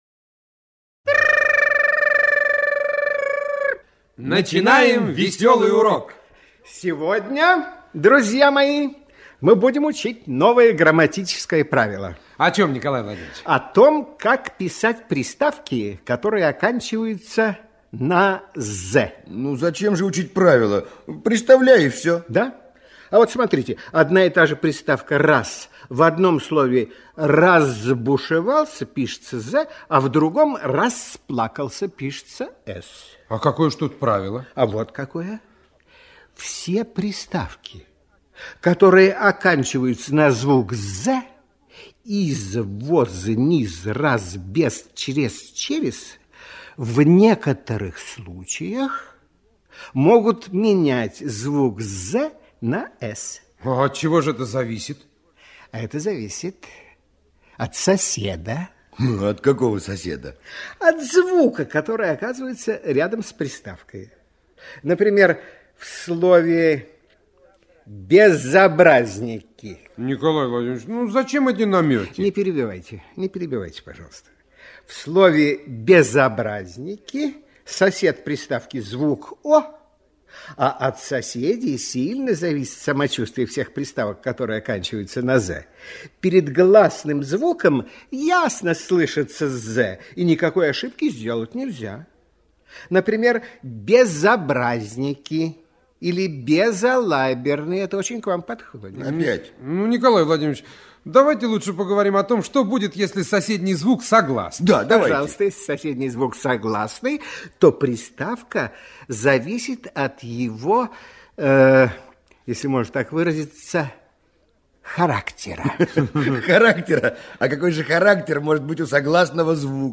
В этом разделе размещены аудиоуроки для школьников из образовательной программы «Радионяня», которая транслировалась на всесоюзном радио в 1970-1980 г.
«Весёлые уроки радионяни» в шутливой музыкальной форме помогают детям запомнить правила русского языка..